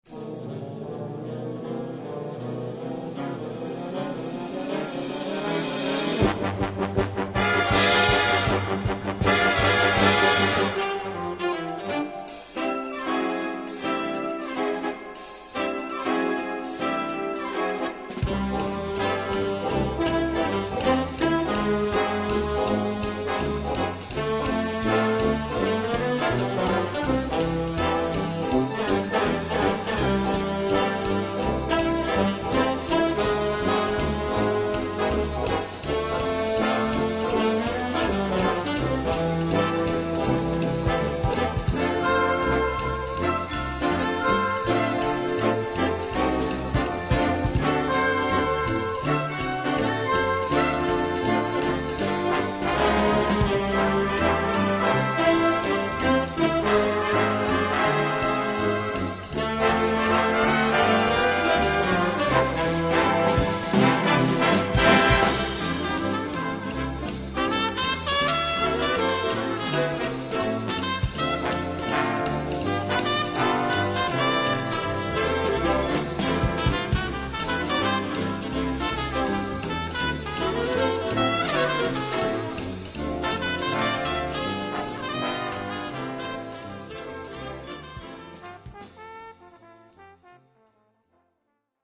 Gattung: Swing
Besetzung: Blasorchester